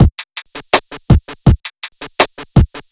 HP082BEAT4-L.wav